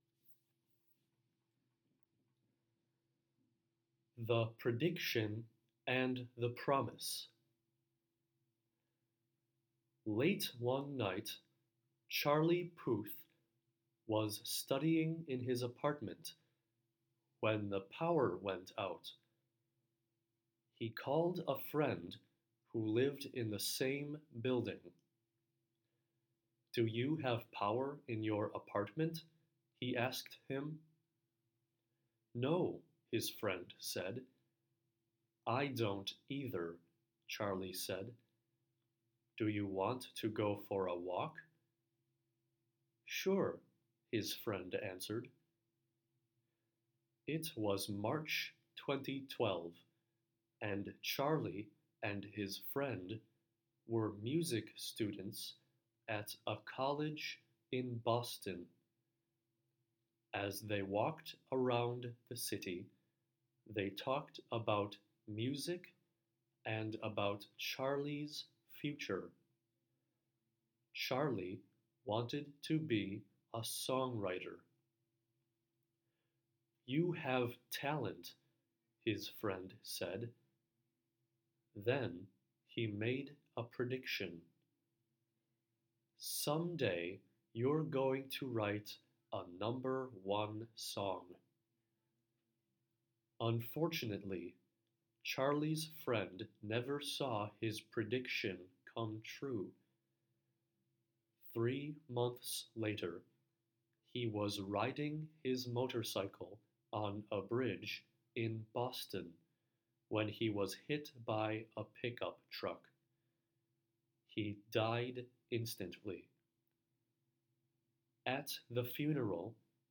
Level 2: High Beginning
Voice-over